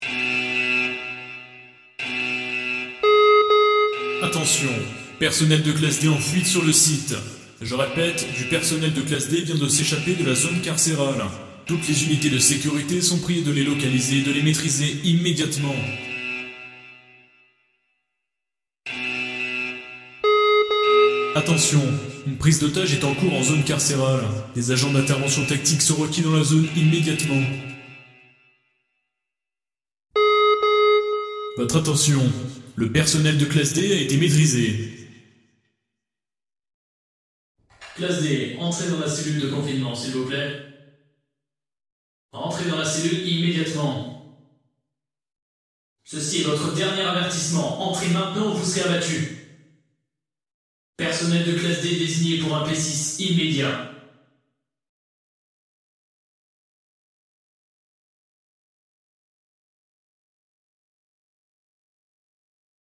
pack dalertes et intercom de classe d.mp3
pack-dalertes-et-intercom-de-classe-d.mp3